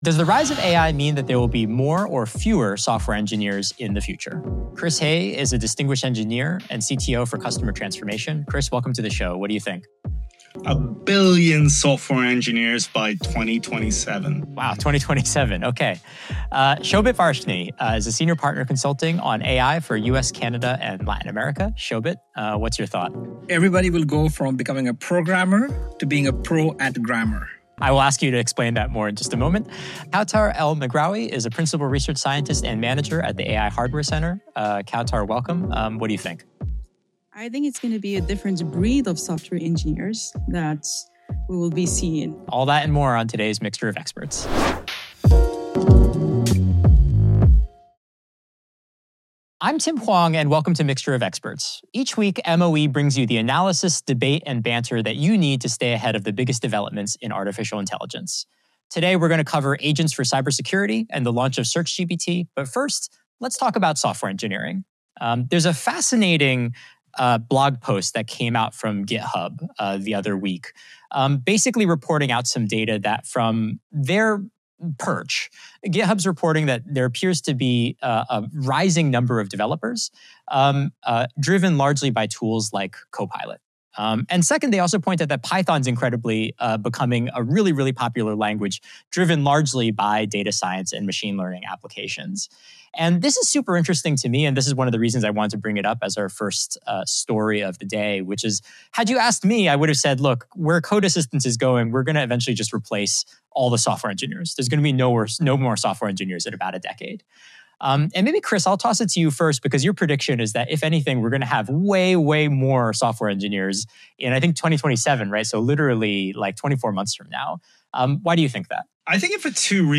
First, the experts dissect Machines of Loving Grace, a 15,000 word essay written by Anthropic’s CEO making some major AI predictions.